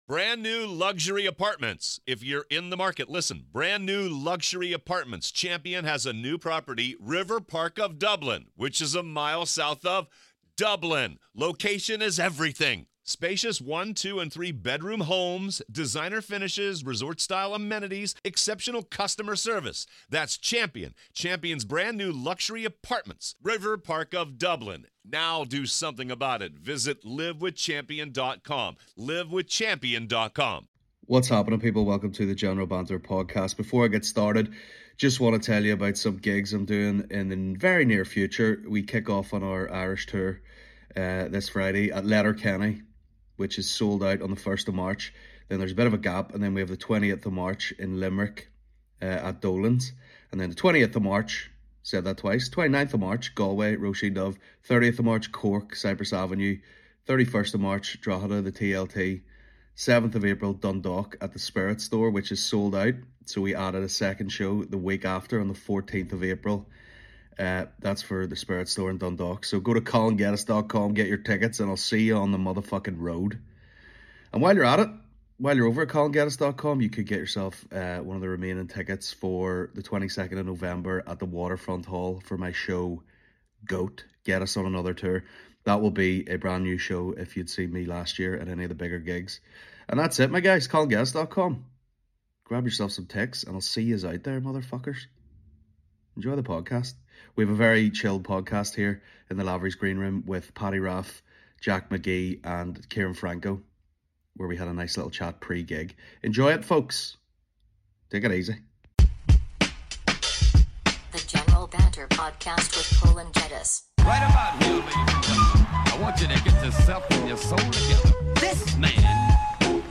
On this week's podcast we chat Mental Audiences, Jimmy Saville, Elephants in Belfast Riots, Butter usages, The Devenish and much more in The Lavery's Comedy Club Green room